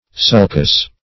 Sulcus \Sul"cus\, n.; pl. Sulci.